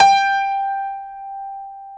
SG1 PNO  G 4.wav